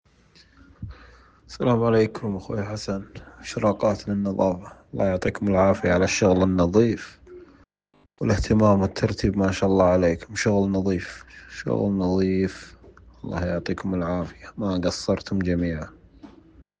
اراء-العملاء.mp3